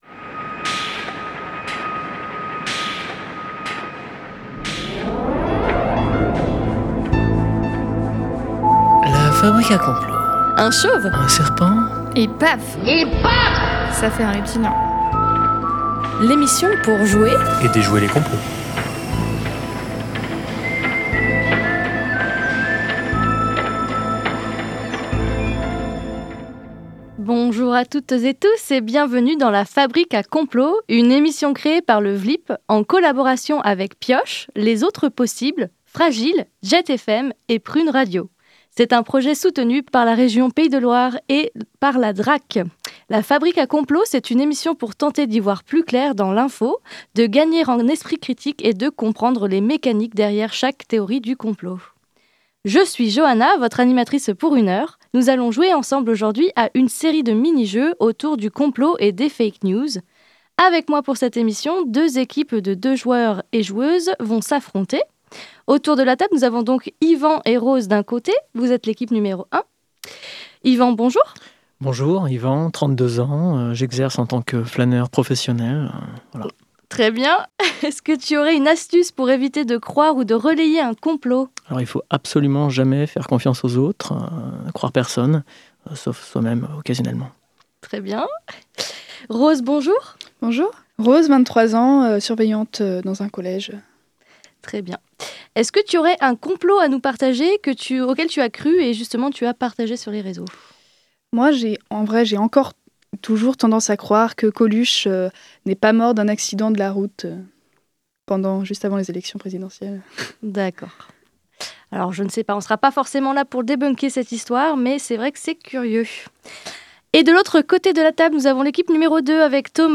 La Fabrique à Complots : un jeu radiophonique avec le Vlipp - Sonolab
La Fabrique à Complots, c’est une émission radiophonique co-construite avec différents médias associatifs nantais et de jeunes étudiant.e.s de l’université de Nantes. Le Vlipp, une association audiovisuelle nantaise, est à l’initiative de ce projet ambitieux : la création, de A à Z, d’un jeu radiophonique pour débunker les fake news !
Au travers d’ateliers, tous les mardis soirs de février à fin mars, les étudiant.e.s ont appris à mieux cerner la logique d’un jeu, à utiliser des outils pour décrypter les informations et créer un habillage sonore avec l’équipe de Jet, pour finir sur le plateau de Prun’ afin de présenter leur jeu radiophonique !